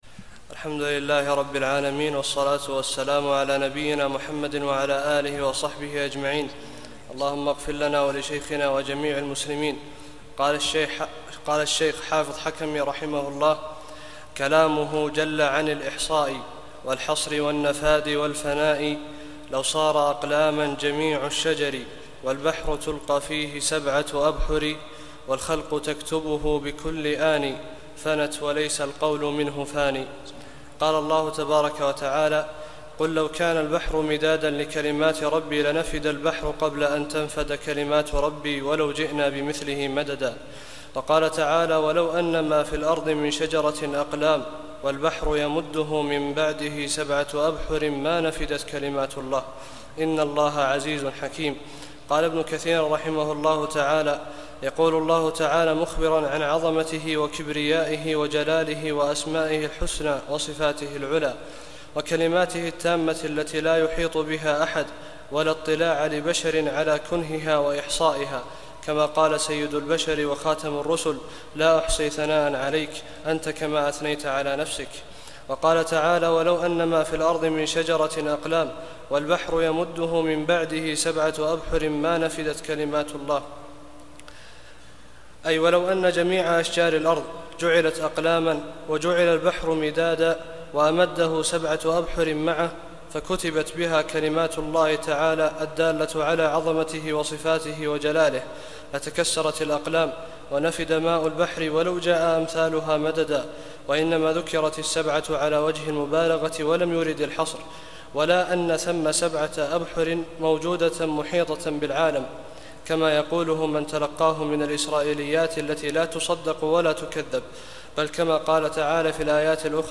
35- الدرس الخامس والثلاثون